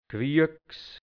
Mundart-Wörter | Mundart-Lexikon | hianzisch-deutsch | Redewendungen | Dialekt | Burgenland | Mundart-Suche: G Seite: 21